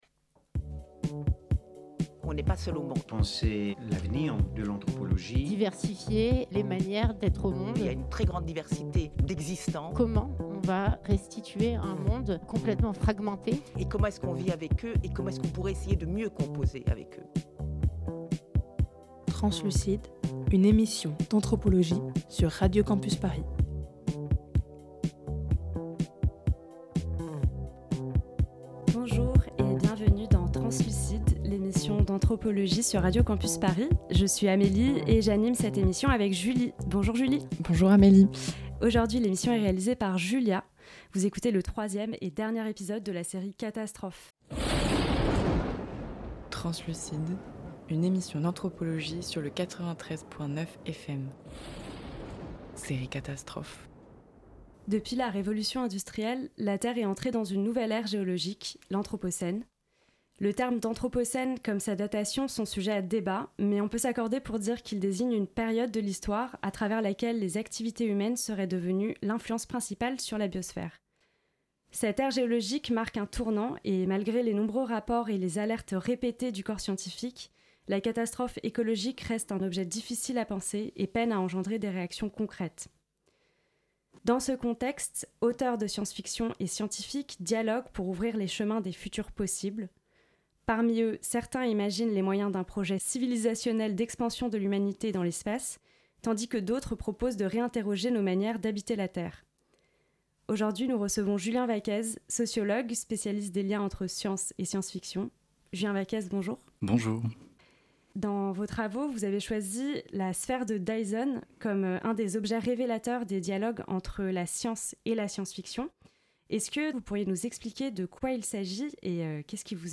Partager Type Entretien Société lundi 11 septembre 2023 Lire Pause Télécharger EPISODE 3 - série Catastrophe Quand la science-fiction fait science.